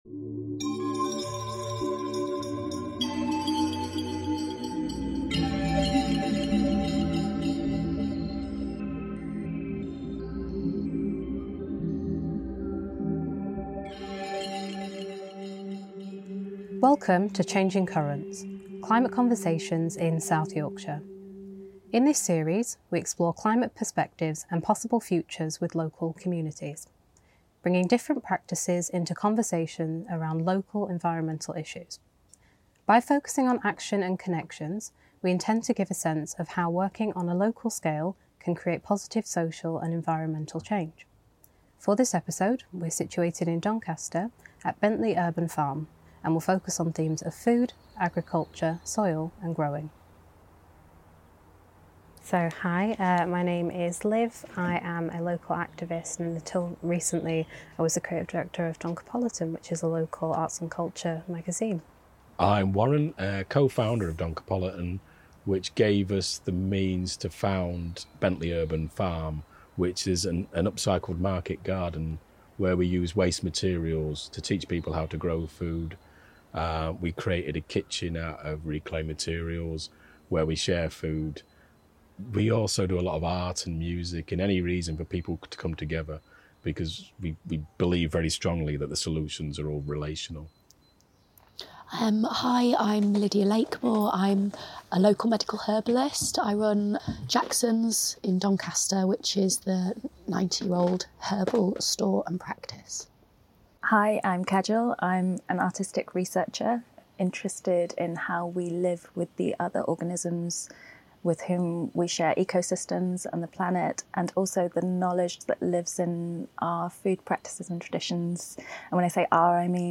Changing Currents is a new podcast series exploring climate perspectives and possible futures featuring the voices of artists, growers, activists, local community groups, heritage workers and researchers across South Yorkshire.